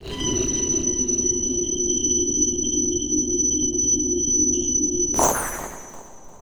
Sudden Interference.wav